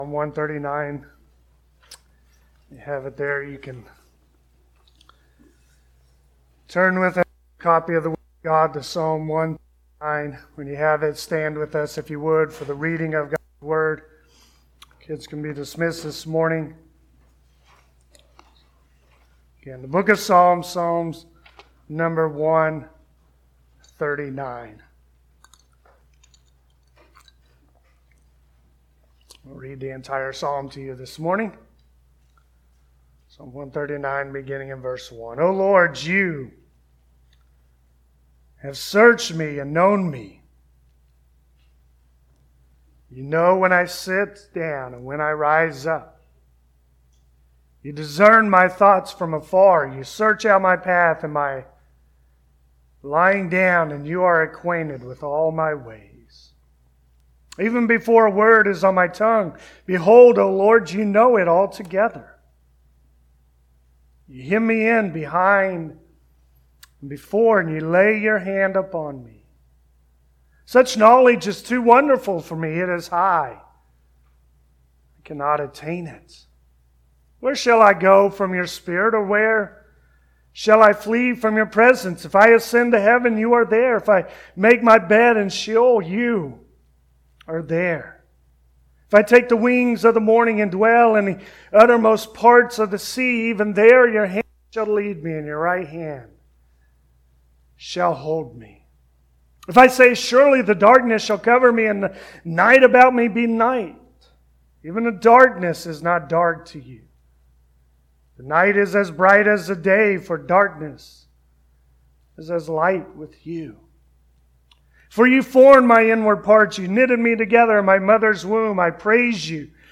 Passage: Psalm 139 Service Type: Sunday Morning The psalmist rejoices in a God who is intimately involved in every aspect of his life.